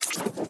Cybernetic Technology Affirmation 8.wav